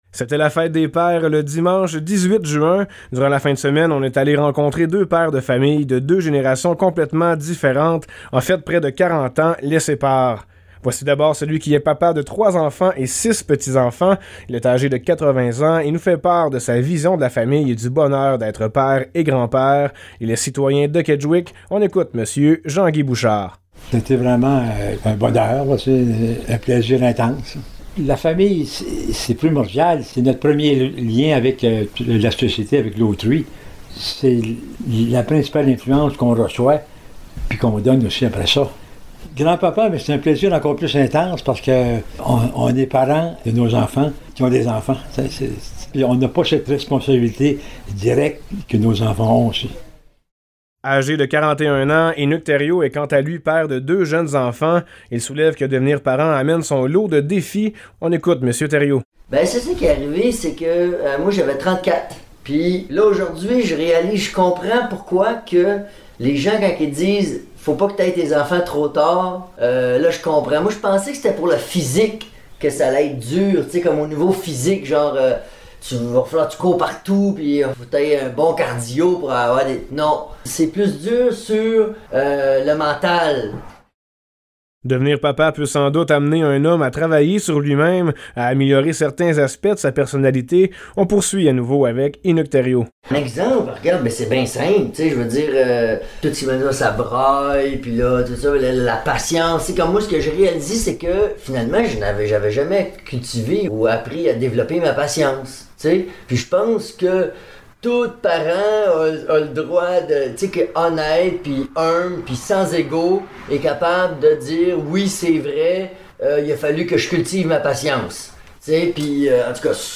Pour souligner la fête des Pères qui avait lieu le dimanche 18 juin, deux pères séparés par les époques partagent leur vision de la famille et leur expérience envers ce rôle paternel.
Reportage